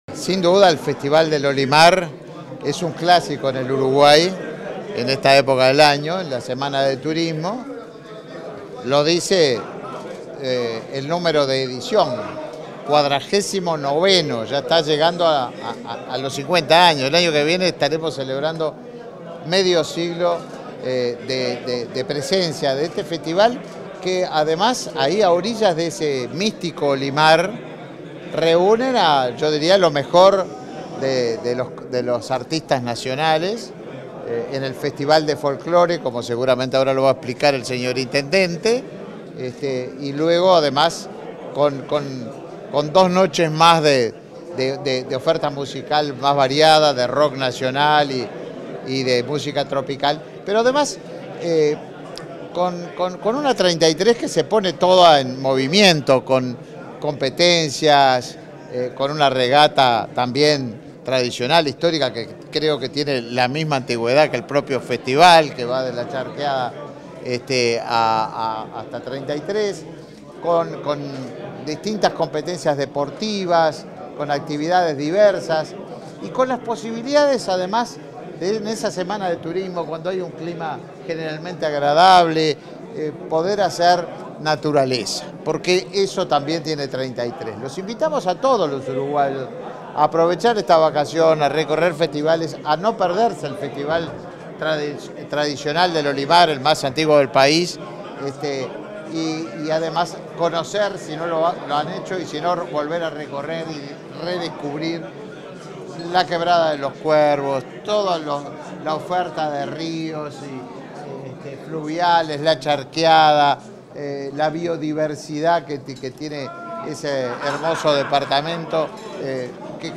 Declaraciones del ministro de Turismo y el intendente de Treinta y Tres
Declaraciones del ministro de Turismo y el intendente de Treinta y Tres 28/03/2023 Compartir Facebook X Copiar enlace WhatsApp LinkedIn Tras el lanzamiento de una nueva edición del Festival del Olimar, este 28 de marzo, el ministro de Turismo, Tabaré Viera, y el intendente de Treinta y Tres, Mario Silvera realizaron declaraciones a la prensa.